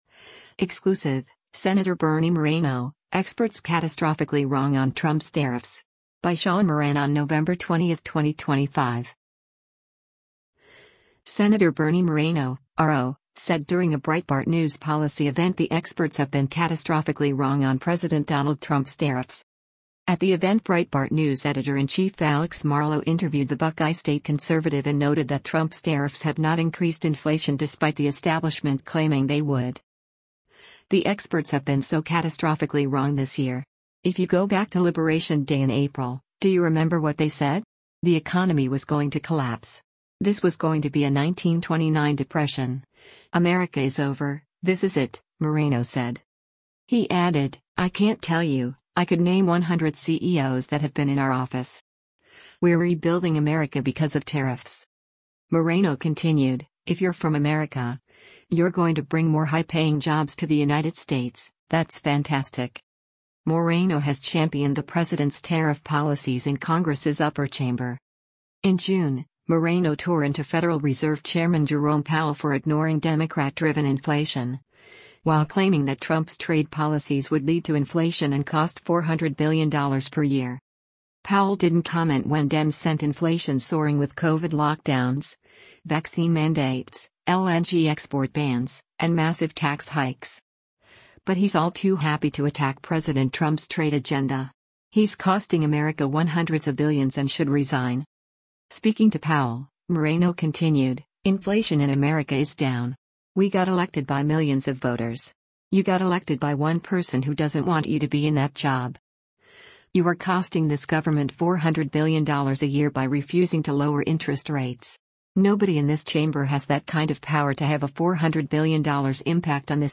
Sen. Bernie Moreno (R-OH) said during a Breitbart News policy event the experts have been “catastrophically wrong” on President Donald Trump’s tariffs.